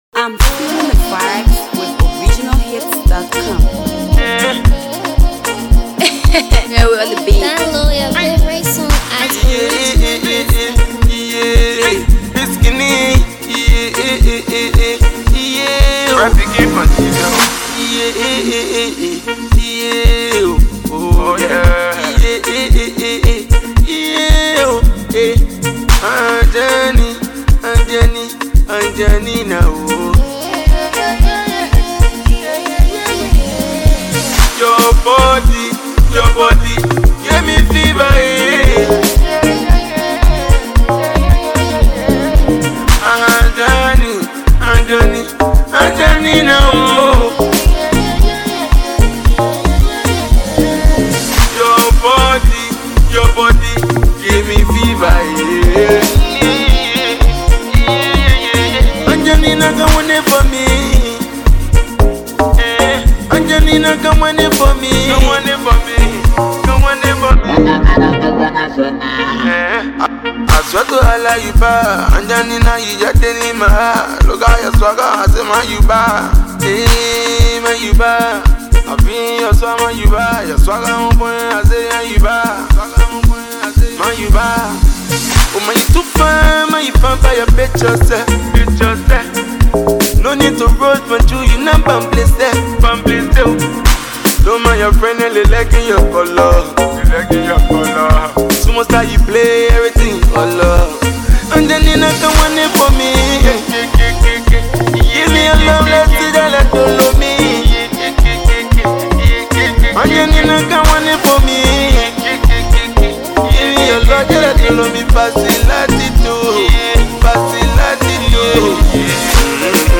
This compelling banger is bound to captivate your ears